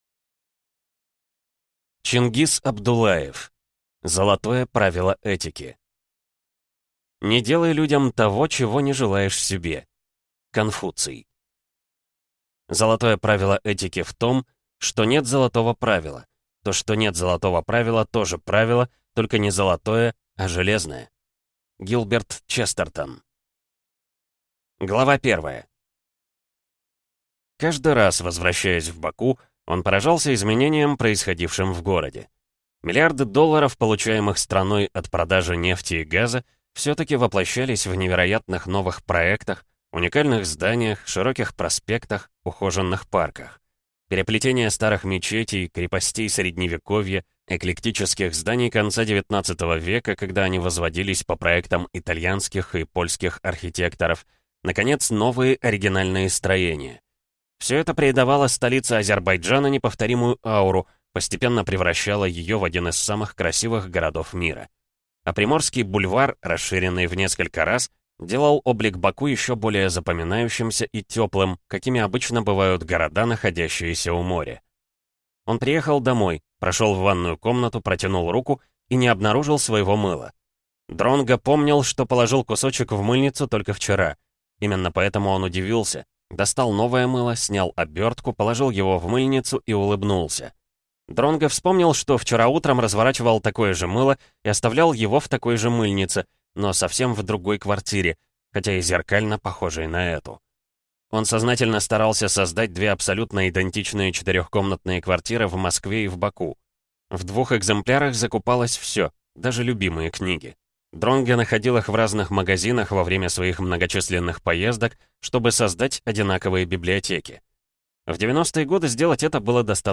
Аудиокнига Золотое правило этики | Библиотека аудиокниг